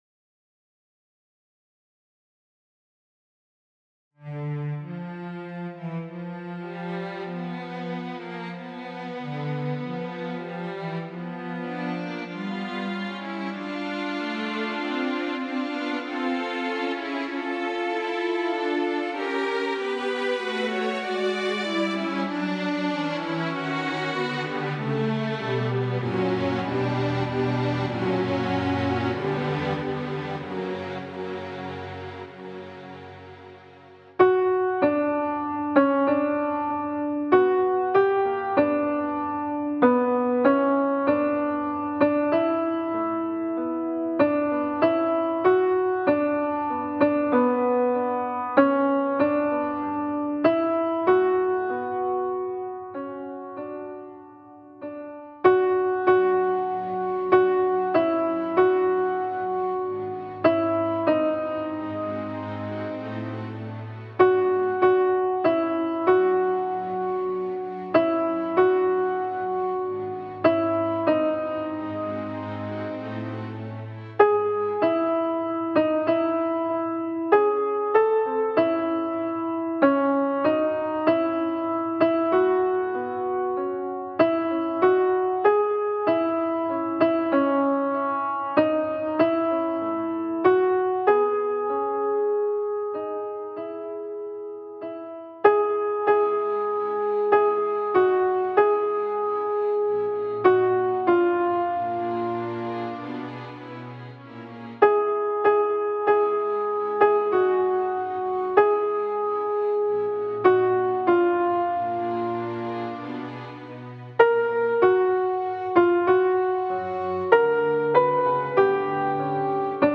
Contralto
Mp3 Música
2.-Offertoire-CONTRALTO-Musica.mp3